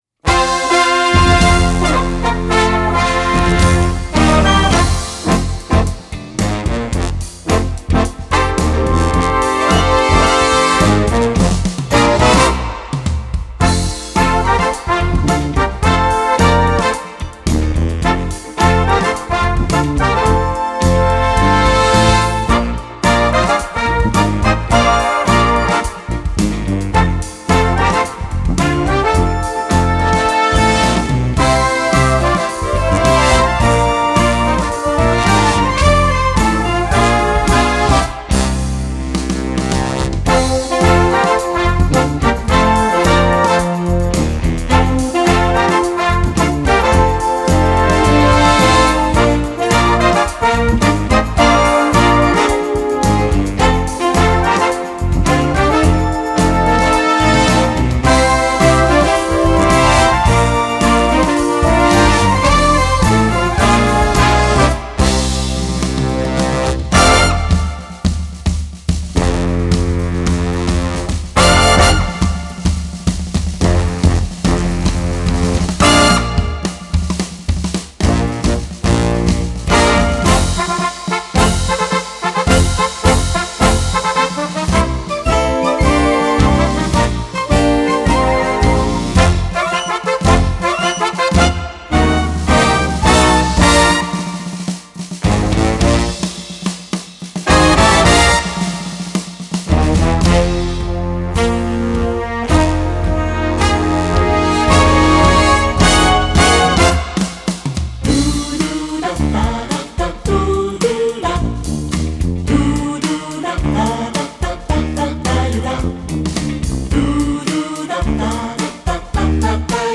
hymnischen March-Rock
Besetzung: Brass Band